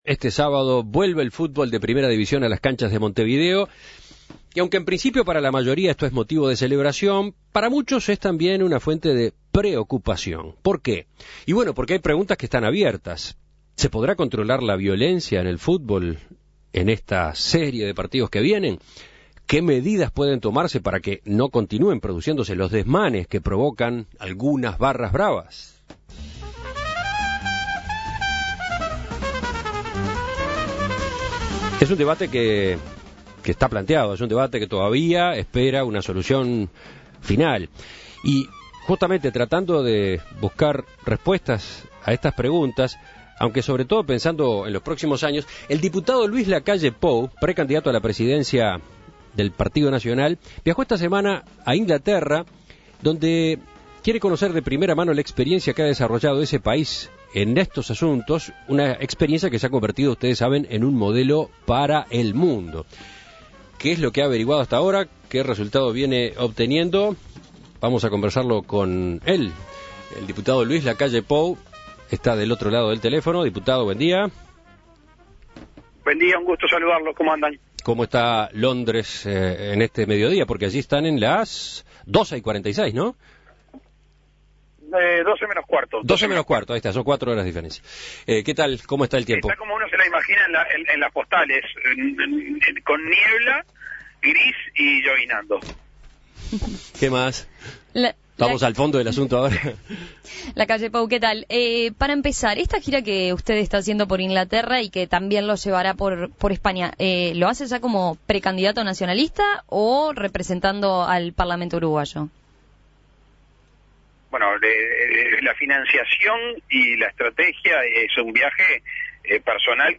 Escuche la entrevista a Luis Lacalle Pou